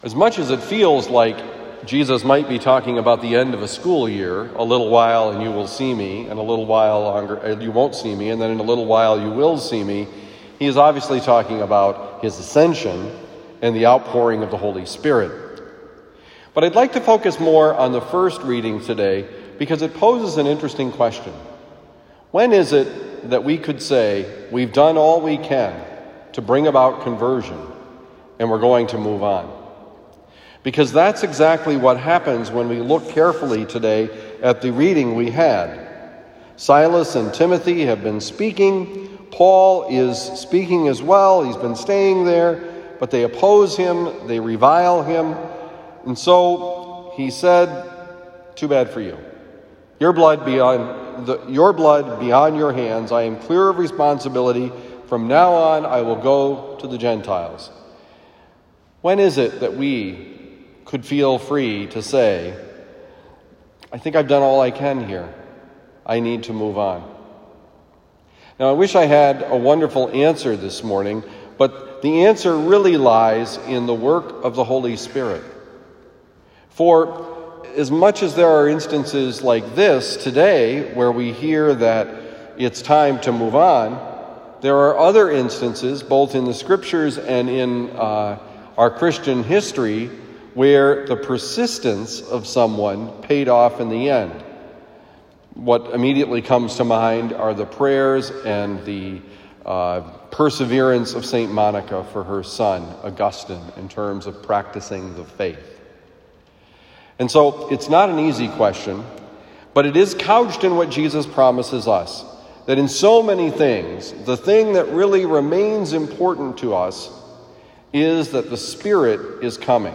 Homily given at Christian Brothers College High School, Town and Country, Missouri.